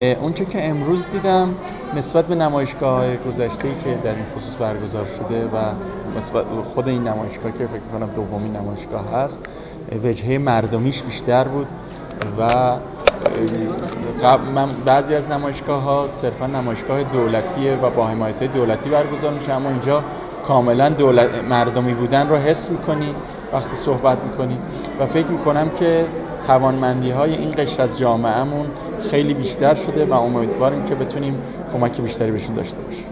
توانگر در گفت‌وگو با ایکنا:
مجتبی توانگر، نماینده مجلس شورای اسلامی در حاشیه افتتاحیه دومین جشنواره ملی هنری «همام» در گفت‌وگو با ایکنا گفت: نسبت به نمایشگاه‌های گذشته که در این خصوص برگزار شده و نسبت به اولین دوره همین نمایشگاه وجهه مردمی بیشتری را شاهد هستیم.